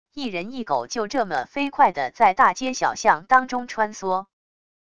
一人一狗就这么飞快地在大街小巷当中穿梭wav音频生成系统WAV Audio Player